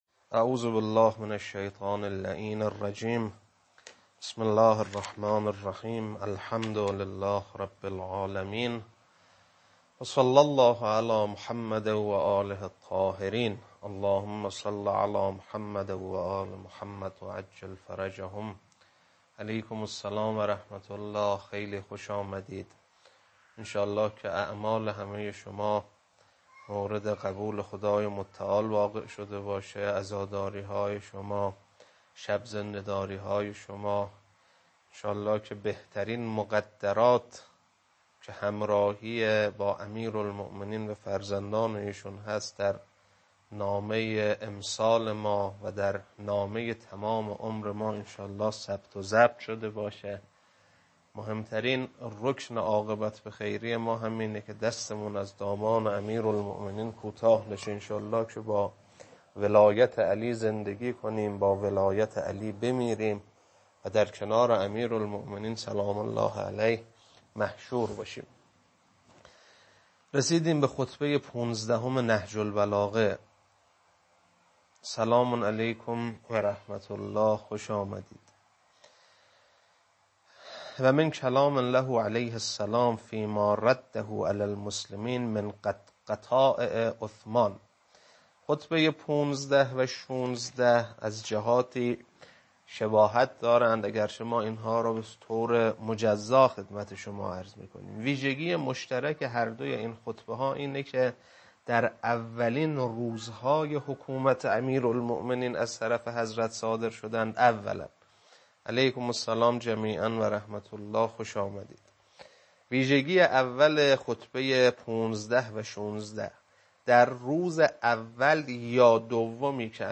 خطبه 15.mp3
خطبه-15.mp3